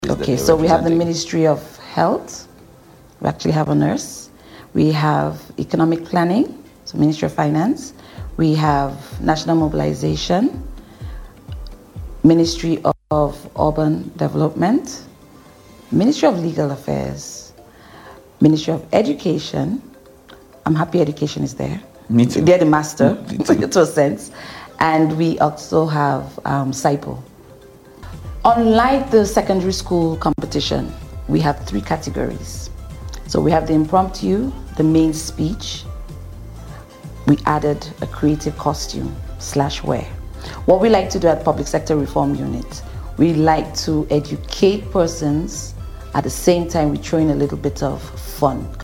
speaking with the Agency for Public Information, API.